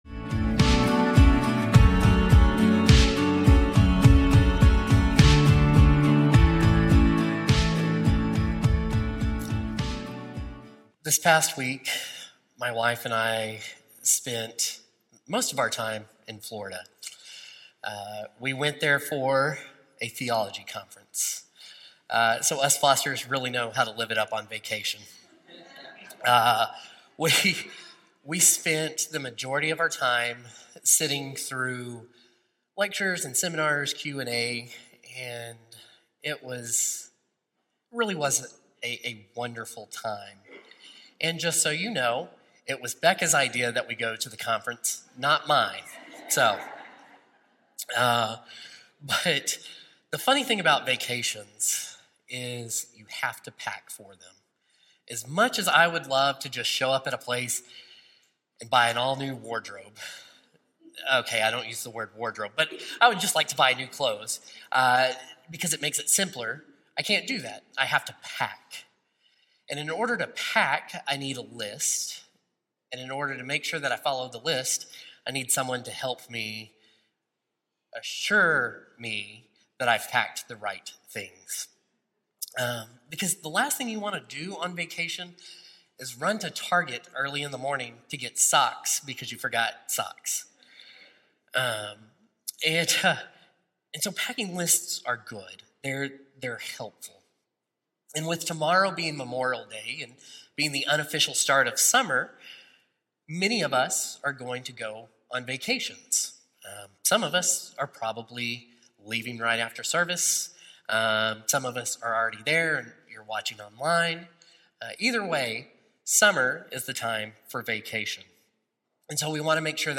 Rooted in the life and teachings of Jesus, the sermon presents a “spiritual packing list”—three key practices to carry with us if we want to stay grounded in God’s presence throughout a busy season: